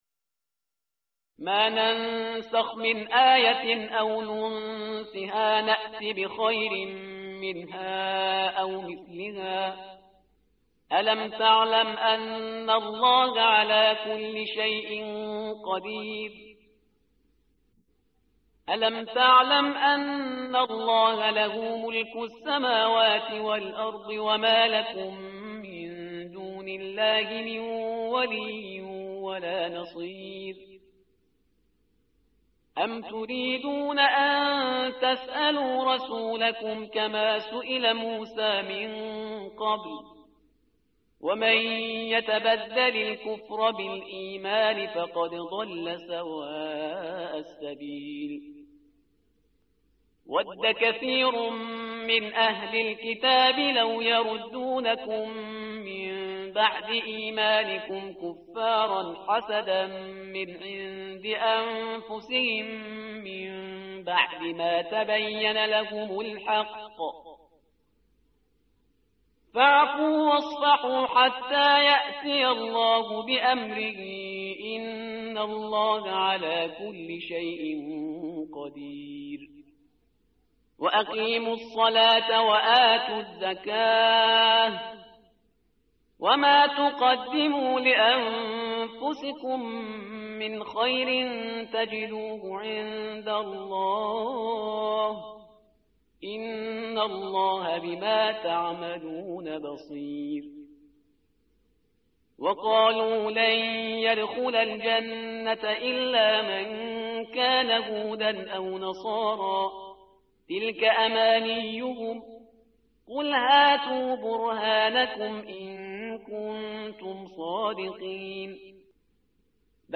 tartil_parhizgar_page_017.mp3